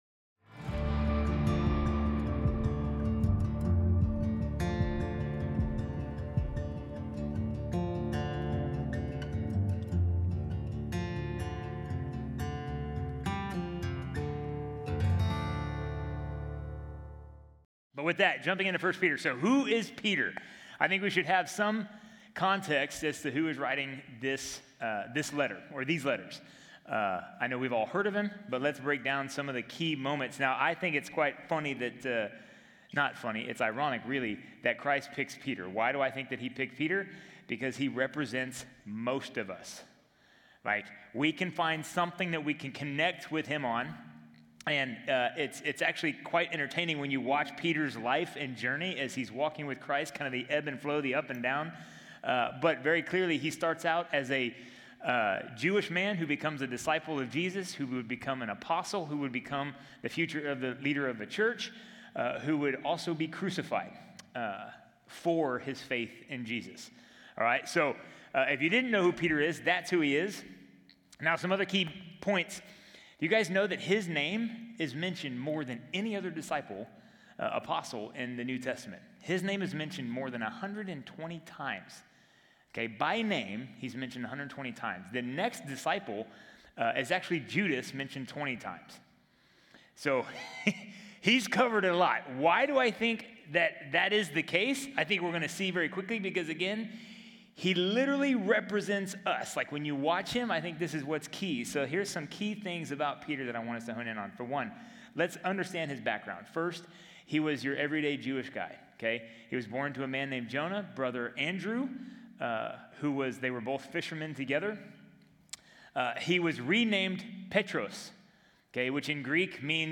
Week 1 of the Men's Study in 1 Peter.